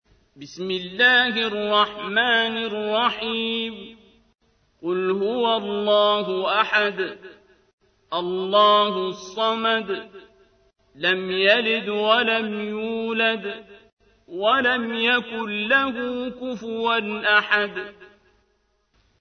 تحميل : 112. سورة الإخلاص / القارئ عبد الباسط عبد الصمد / القرآن الكريم / موقع يا حسين